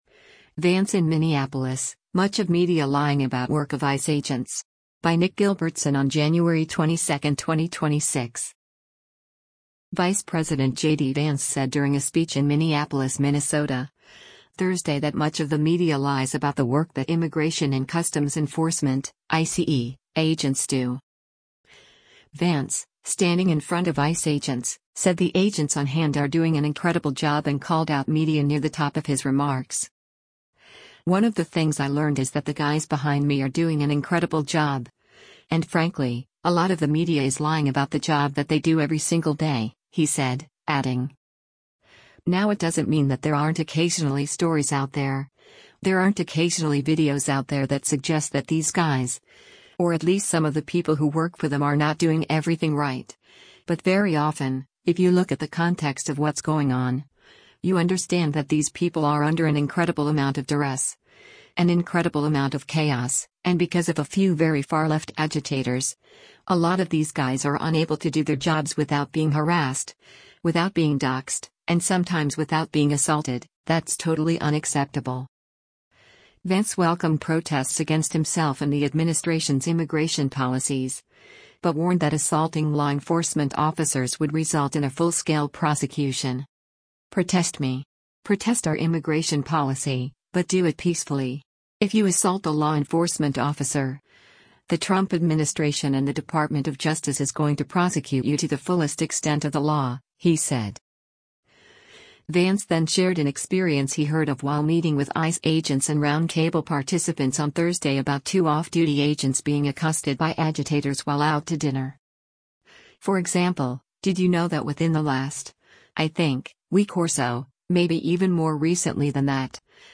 US Vice President JD Vance speaks during a press conference in Minneapolis, Minnesota, US,
Vice President JD Vance said during a speech in Minneapolis, Minnesota, Thursday that much of the media lies about the work that Immigration and Customs Enforcement (ICE) agents do.
Vance, standing in front of ICE agents, said the agents on hand “are doing an incredible job” and called out media near the top of his remarks.